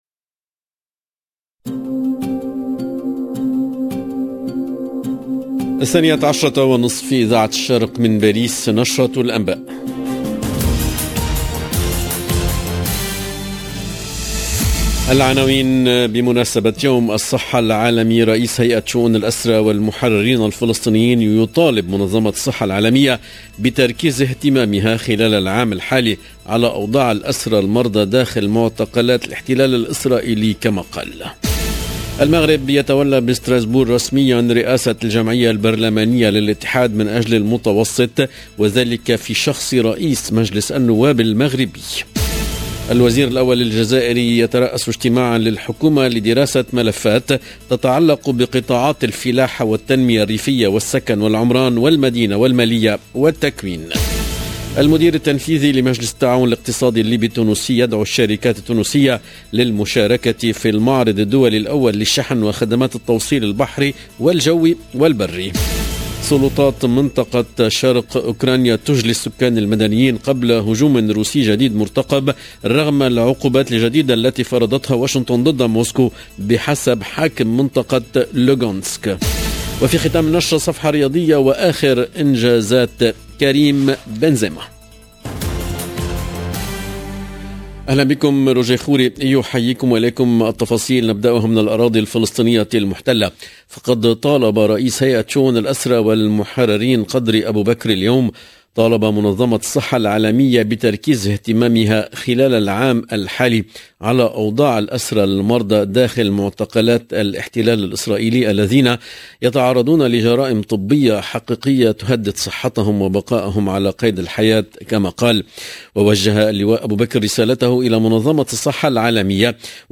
EDITION DU JOURNAL DE 12H30 EN LANGUE ARABE DU 7/4/2022